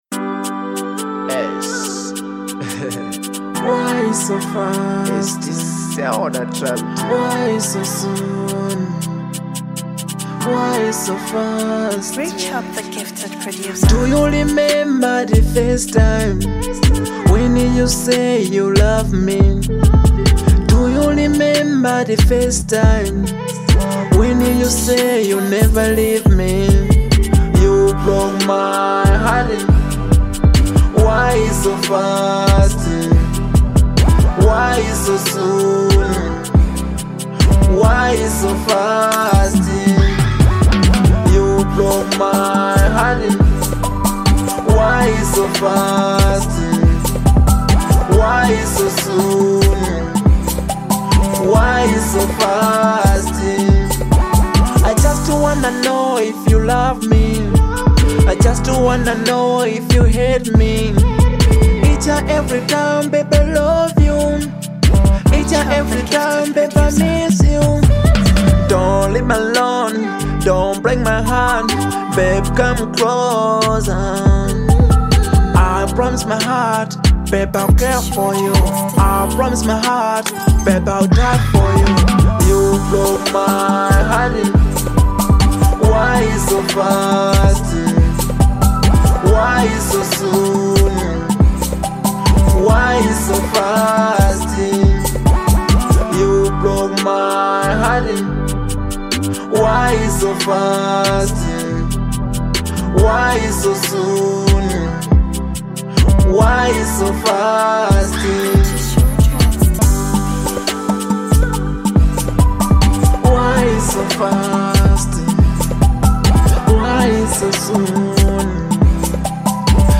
Genre : Drill/Hiphop